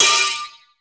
METL.P PIPE.wav